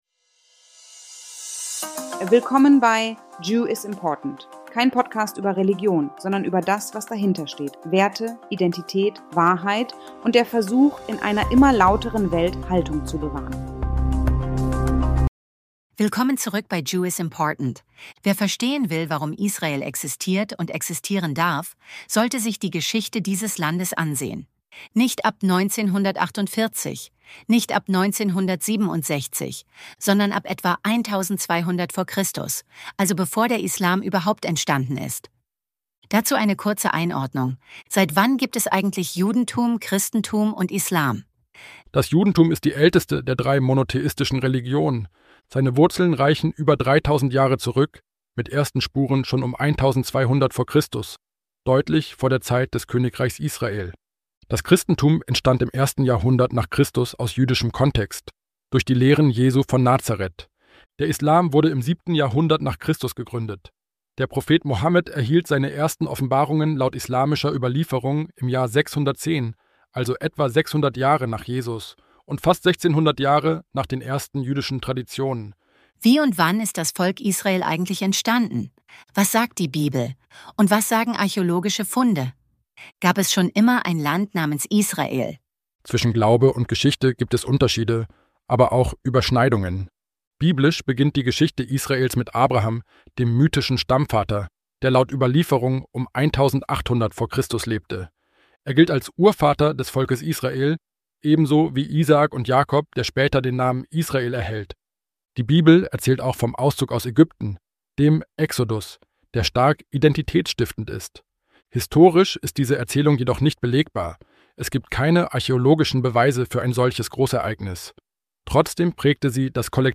© 2025 AI-generated content.